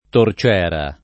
tor©$ra] (raro torcera [id.]) s. f. — nel sign. originario di «sostegno per torce», anche torciere [tor©$re]: grandi torcieri di ferro battuto [gr#ndi tor©$ri di f$rro batt2to] (D’Annunzio)